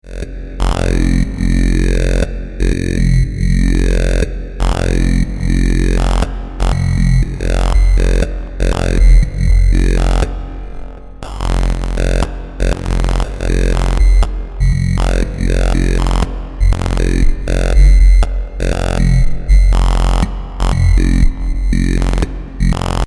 This creates the lovely, complicated “alien voice” sound. Here’s a short MachineDrum loop I was playing around with when I realized what was going on here: